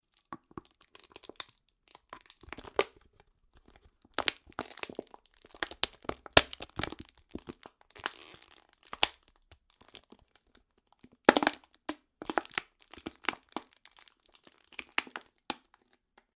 plastic coffee bottle asmr - crushed - flattened.mp3
Recorded with a Steinberg Sterling Audio ST66 Tube, in a small apartment studio.
plastic_coffee_bottle_asmr_-_crushed_-_flattened_gr4.ogg